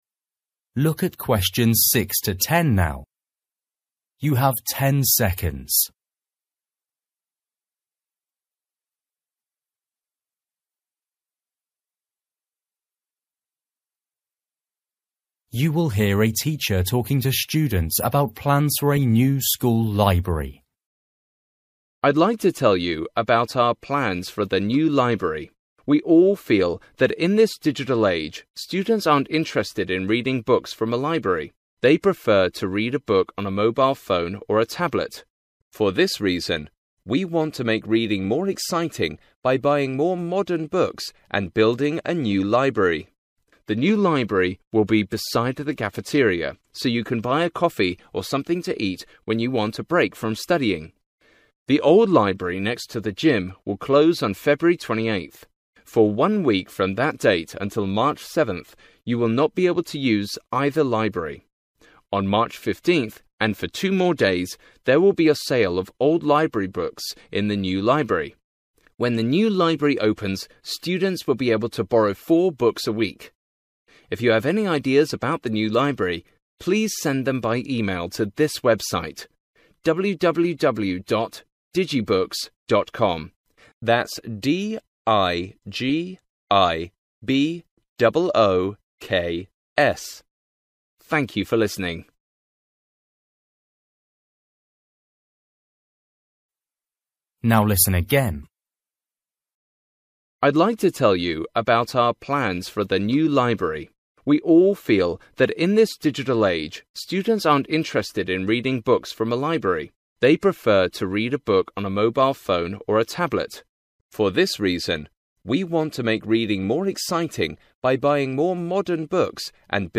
You will hear a teacher talking to students about plans for a new school library.